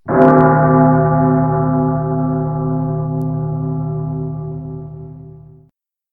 BellLarge.ogg